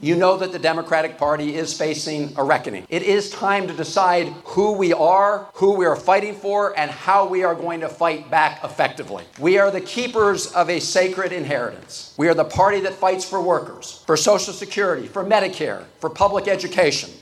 Maryland Senator Chris Van Hollen was a guest speaker at the annual Polk County Steak Fry in Iowa – a crucial gathering for Democrats and their campaigns.  Van Hollen urged those present to continue to speak out vigorously and courageously, but also peacefully…